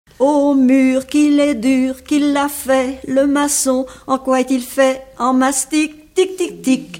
L'enfance - Enfantines - rondes et jeux
Genre brève
Pièce musicale inédite